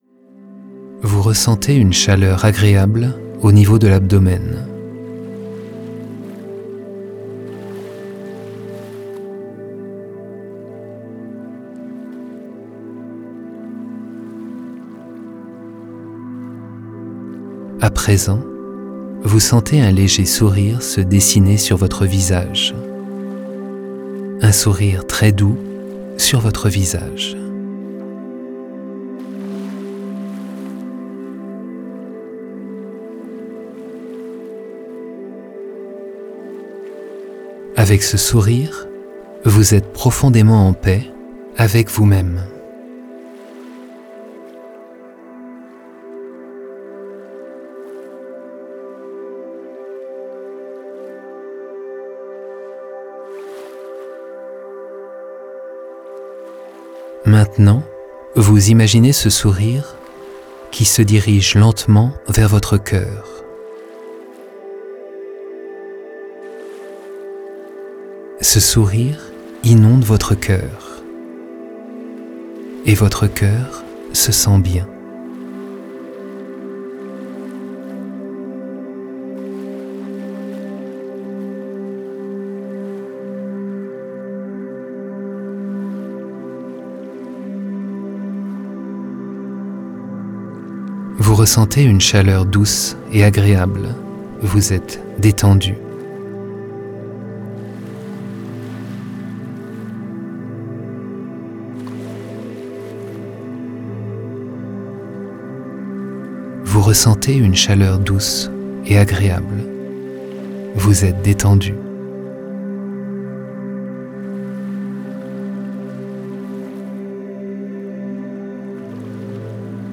Ce livre audio haut de gamme vous amènera efficacement à la détente, de façon presque amusante et totalement indépendante de la religion, des convictions ou de la spiritualité. Les voyages fantasmagoriques (ou imaginaires) font partie des outils psychologiques les plus efficaces à ce jour.